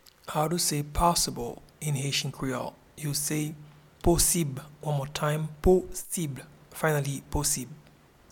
Pronunciation and Transcript:
Possible-in-Haitian-Creole-Posib.mp3